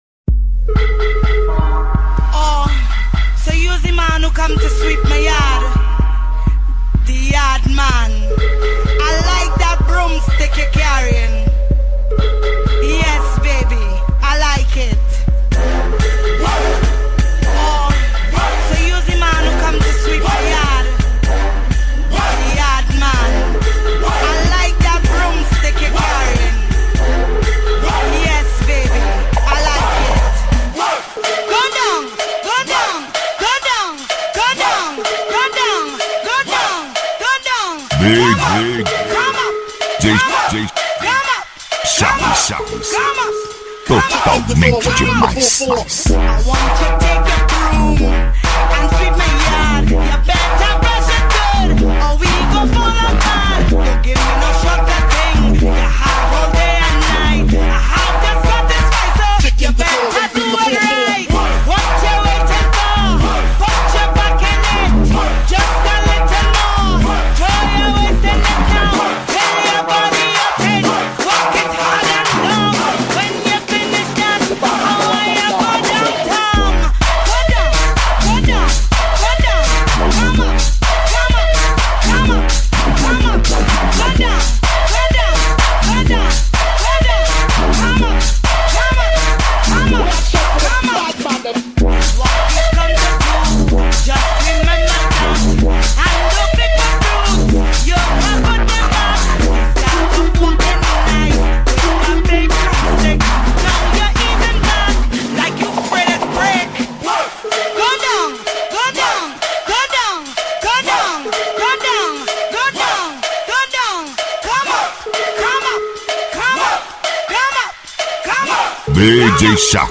DEEP HOUSE.